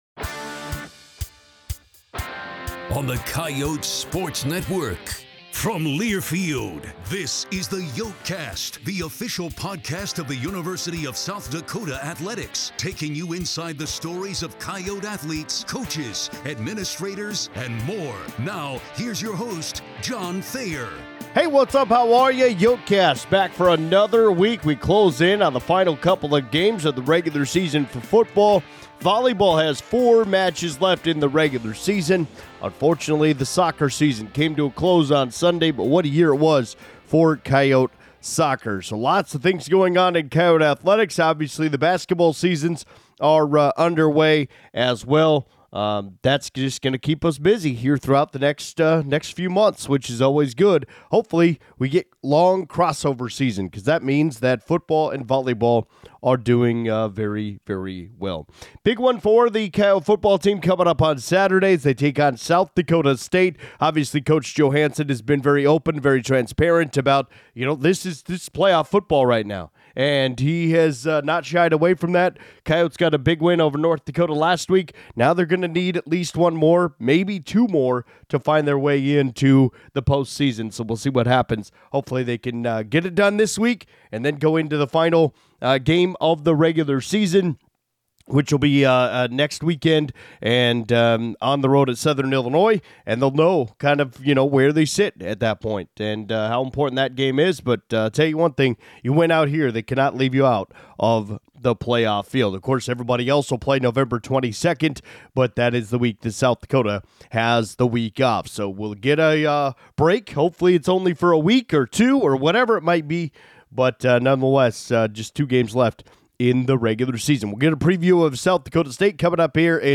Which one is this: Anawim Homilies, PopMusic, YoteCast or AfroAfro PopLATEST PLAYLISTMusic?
YoteCast